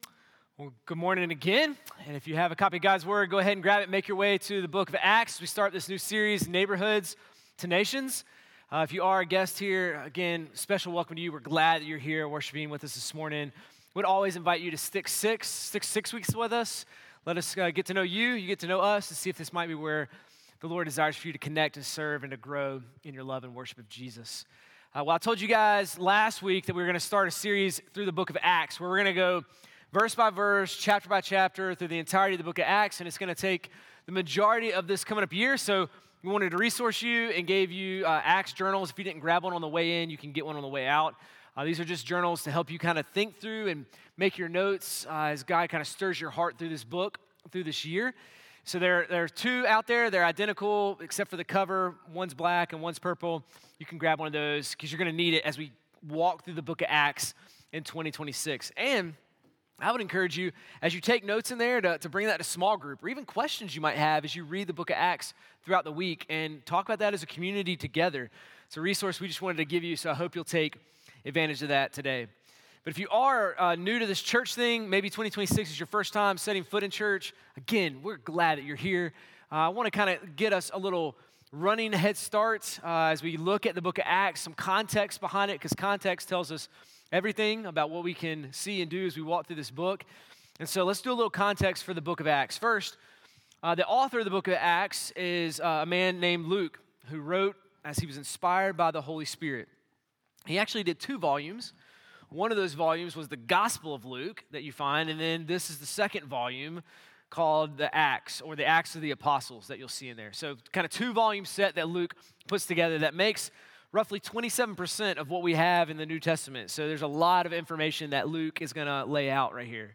sermon-1-11-26.mp3